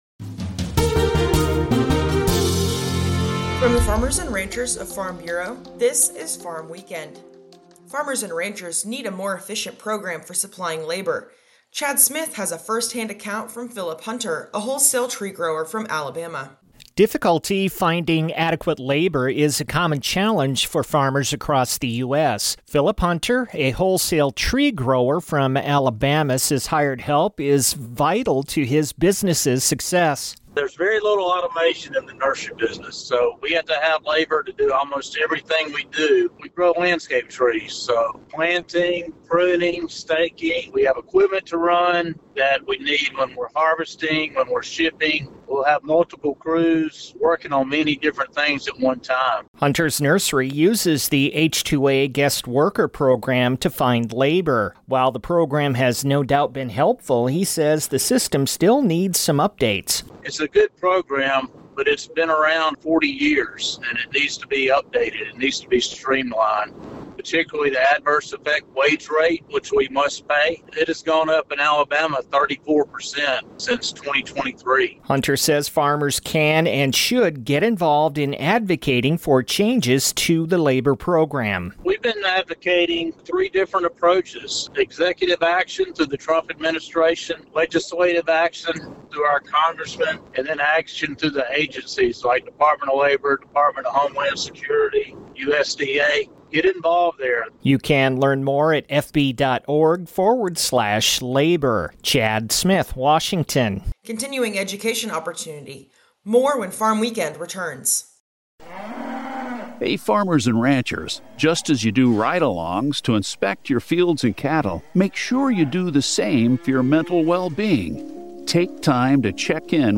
A 5-minute radio program featuring a recap of the week's agriculture-related news and commentary.